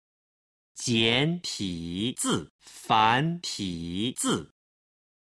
今日の振り返り！中国語発声
01-jiantizi-fantizi.mp3